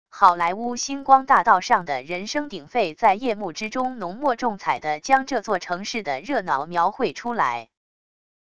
好莱坞星光大道上的人声鼎沸在夜幕之中浓墨重彩地将这座城市的热闹描绘出来wav音频